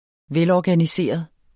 Udtale [ -ɒganiˌseˀʌð ]